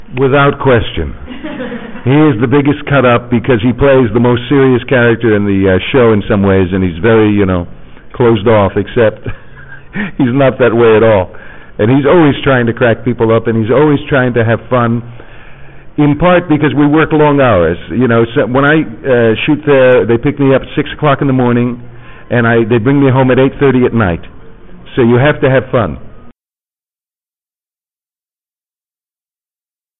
Tony Amendola interview
This interview has been conducted in May 2005 in Paris at the convention "Constellation 4".